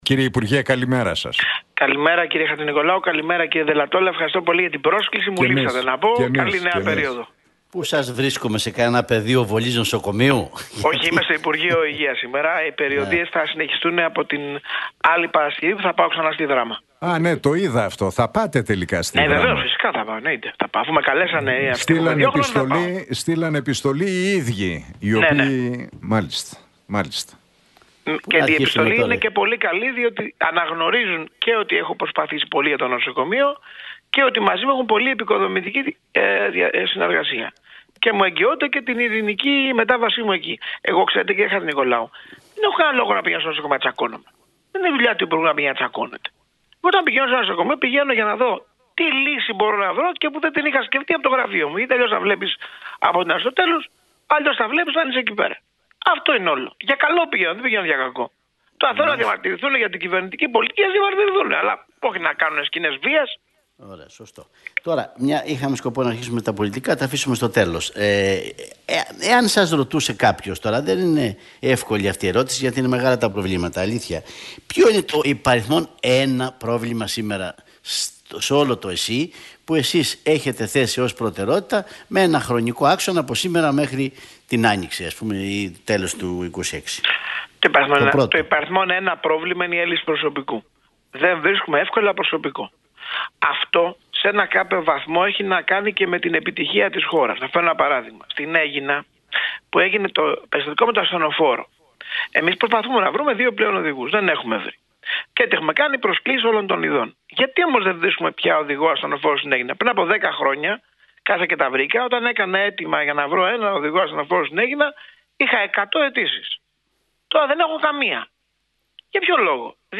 Για την κατάσταση στο ΕΣΥ, τα χειρουργεία αλλά και τις πολιτικές εξελίξεις μίλησε ο υπουργός Υγείας, Άδωνις Γεωργιάδης στον Νίκο Χατζηνικολάου